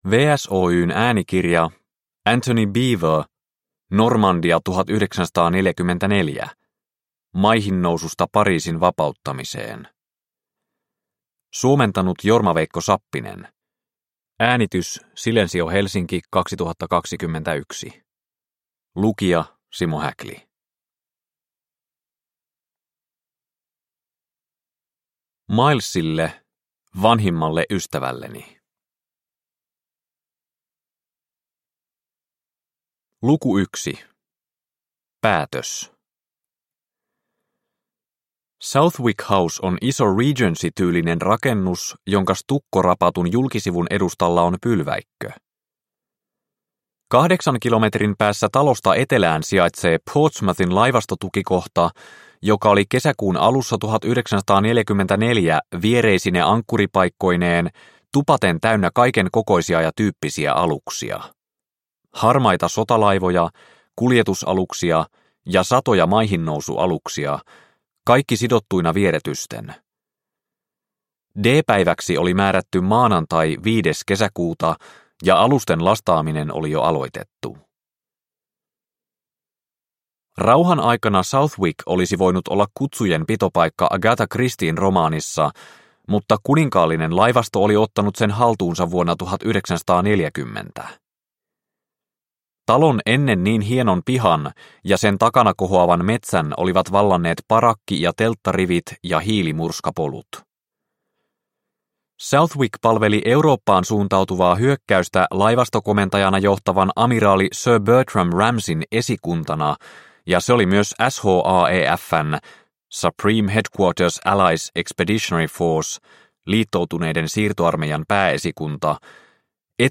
Normandia 1944. Maihinnoususta Pariisin vapauttamiseen – Ljudbok – Laddas ner